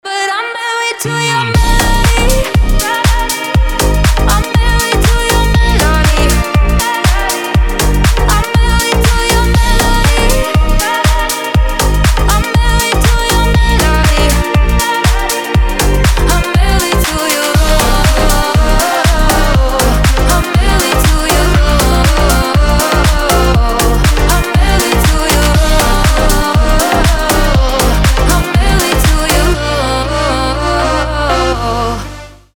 • Качество: 320, Stereo
deep house
Club House